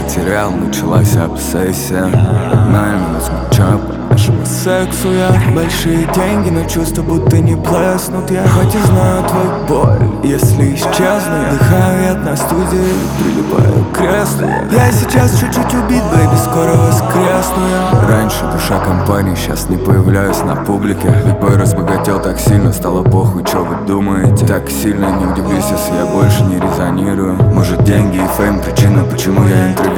Жанр: Хип-Хоп / Рэп / Русский рэп / Русские